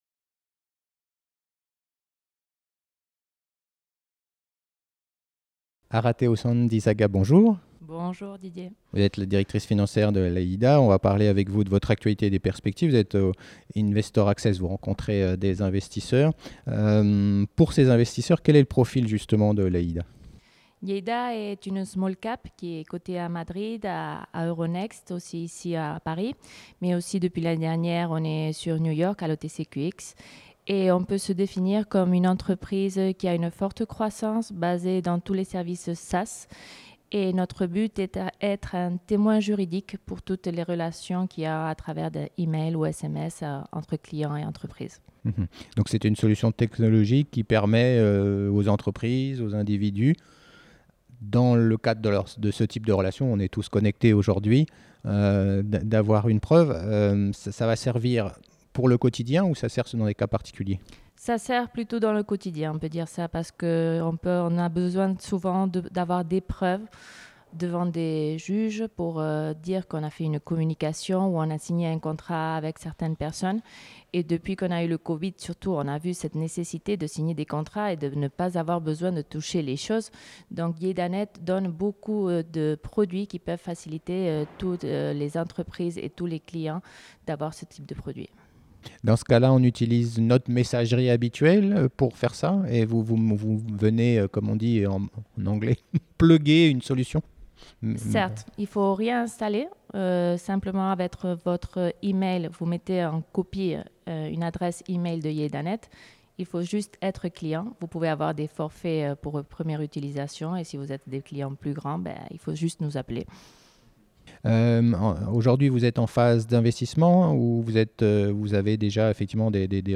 Retour sur la stratégie de l’entreprise et ses perspectives avec mon invitée